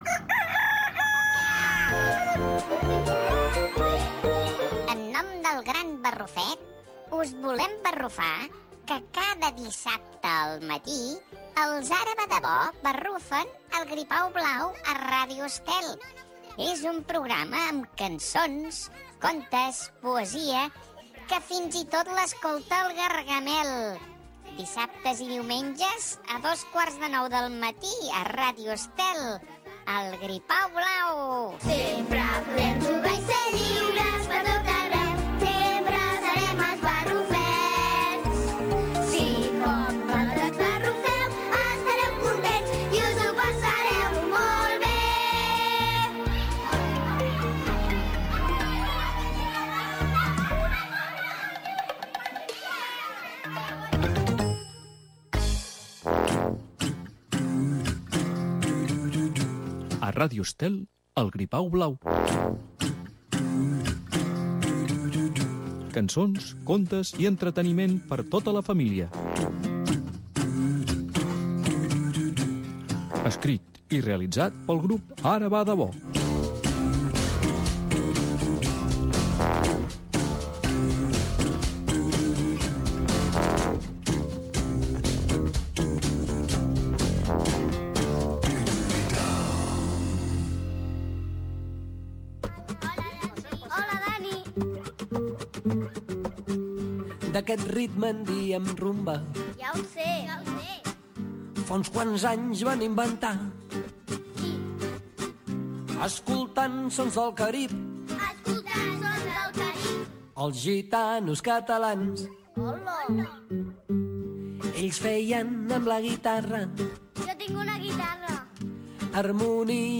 El Gripau blau Programa infantil amb personatges i convidats que proposaran exercicis de gastronomia per a nens, poesia, música i contes.